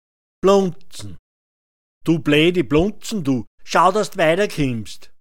Blunzn [‚bluntsn] f
Hörbeispiel Blunzn